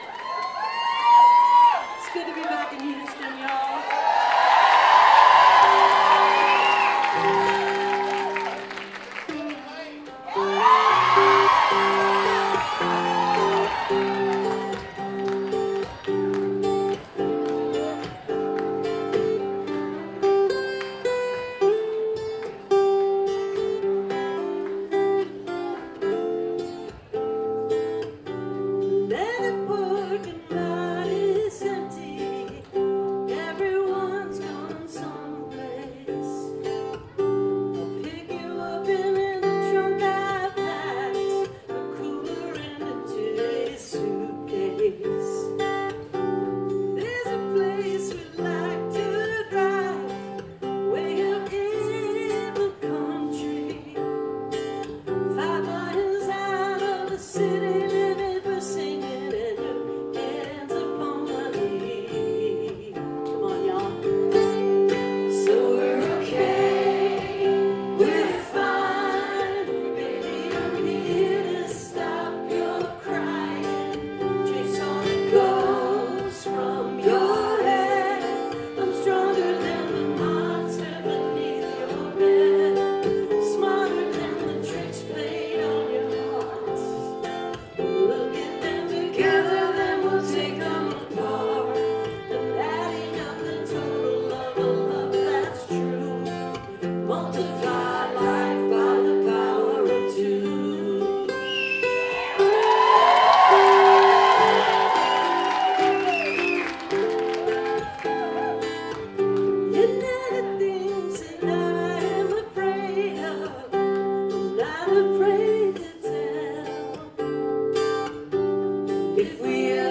live stream on twitch